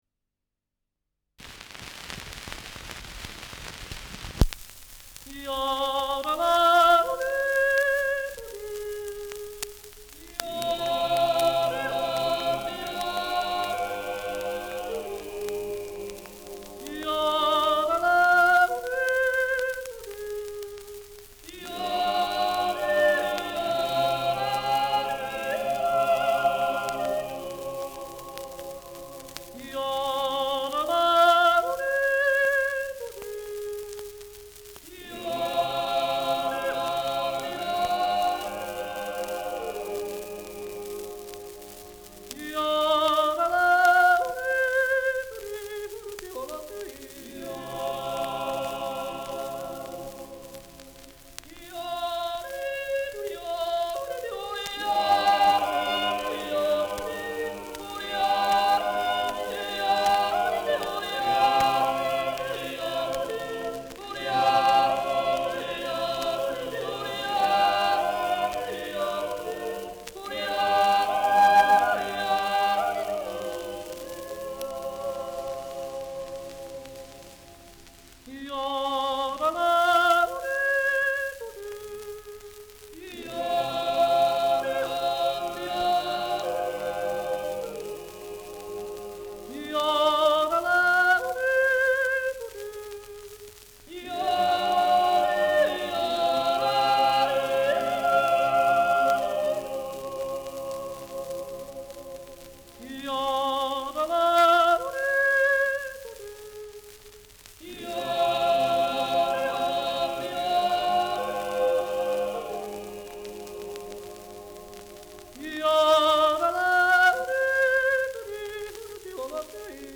Schellackplatte
Jodlergruppe* FVS-00014